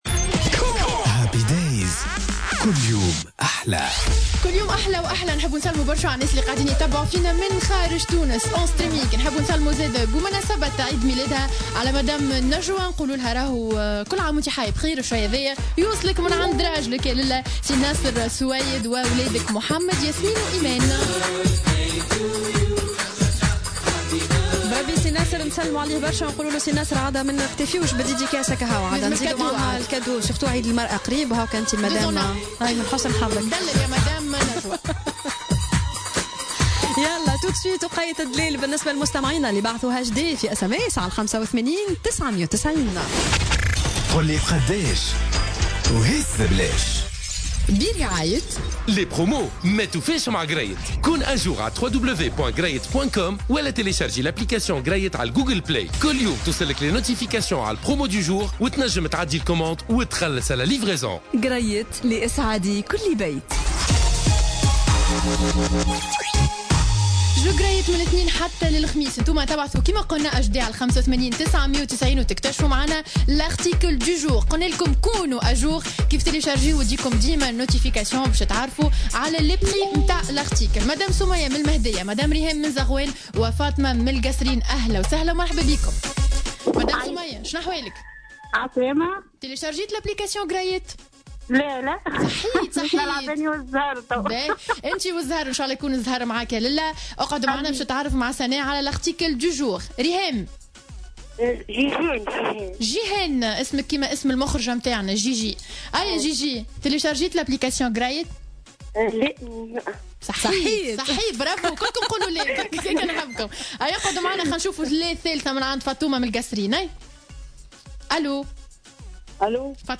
نشرة أخبار منتصف النهار ليوم الثلاثاء 6 مارس 2018